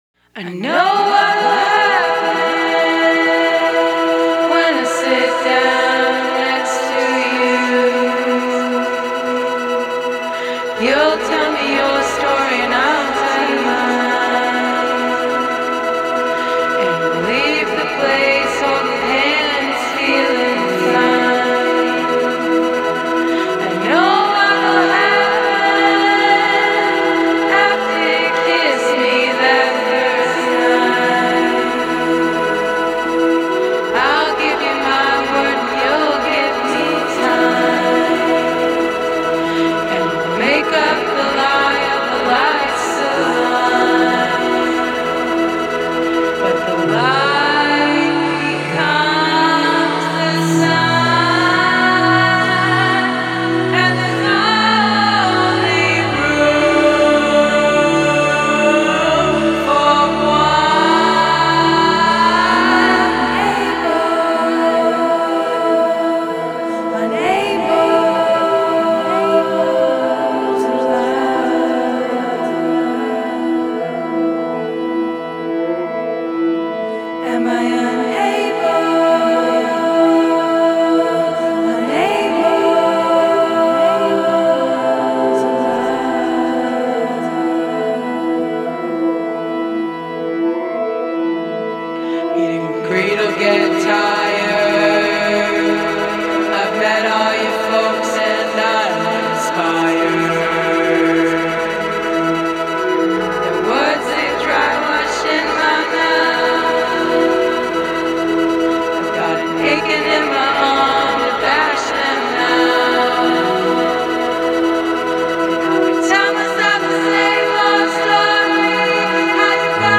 Guitar/Keys/Vocals
Bass/Vocals
Drums/Vocals